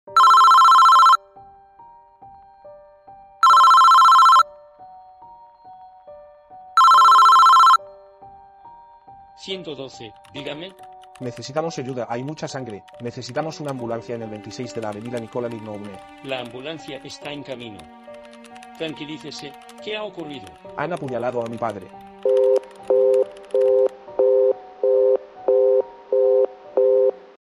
Llamada a urgencias